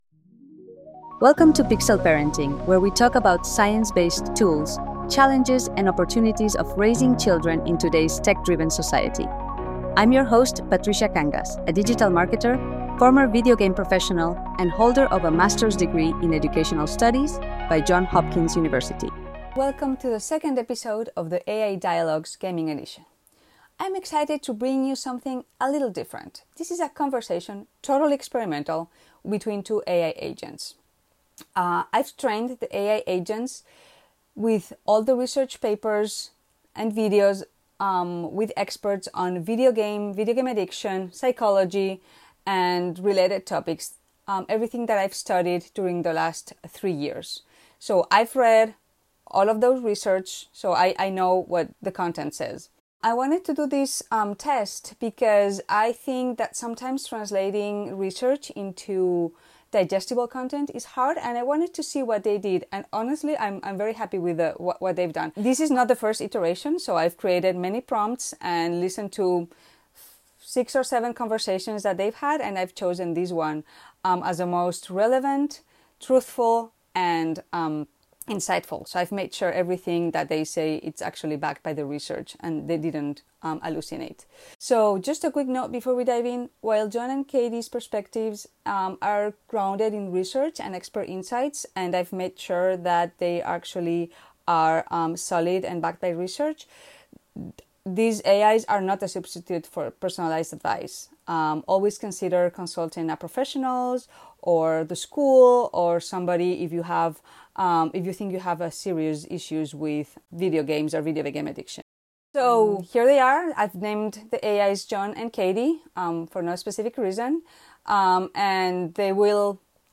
In this episode two of The AI Dialogues we dive into the fascinating world of video games through a unique conversation between two AI agents, John and Kate.